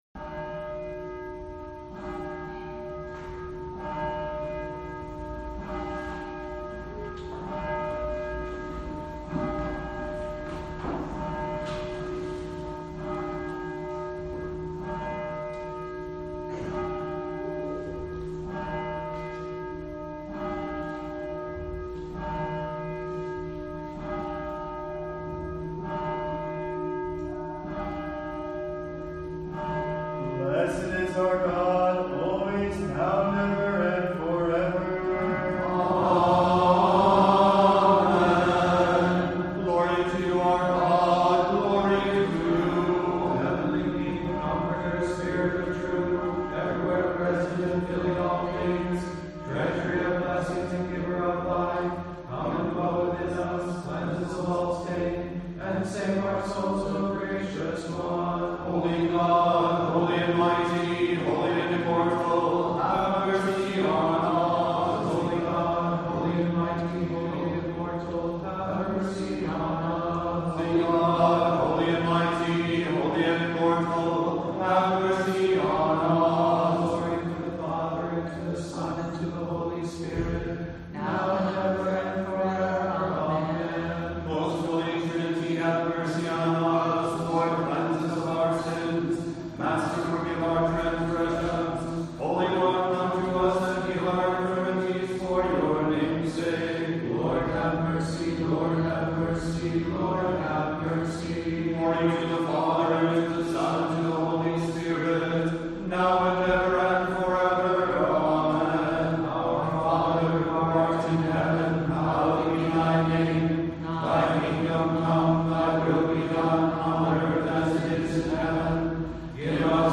Here are the beginning prayers, set to the usual psalm tone.